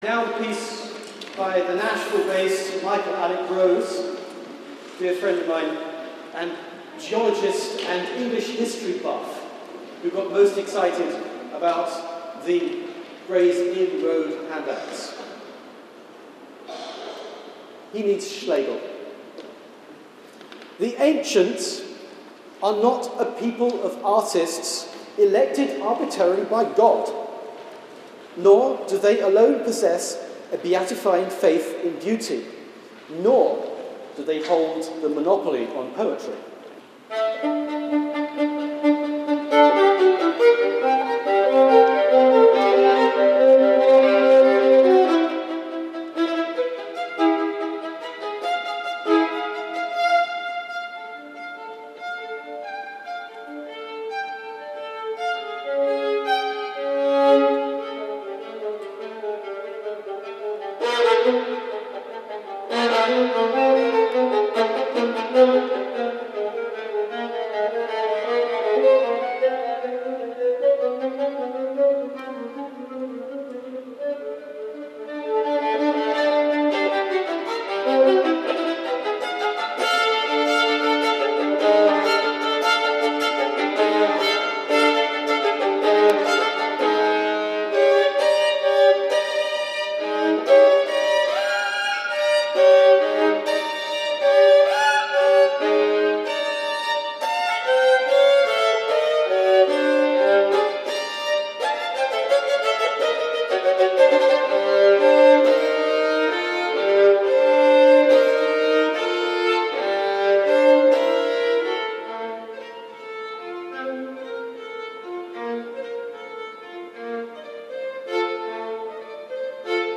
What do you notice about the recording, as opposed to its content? Playing under the Gobi Desert Buddhas (British Museum) Live at the British Museum May 27th 2006